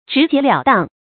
成語注音ㄓㄧˊ ㄐㄧㄝ ˊ ㄌㄧㄠˇ ㄉㄤˋ
成語拼音zhí jié liǎo dàng
直截了當發音
成語正音了，不能讀作“來了”的“le”；當，不能讀作“應當”的“dānɡ”。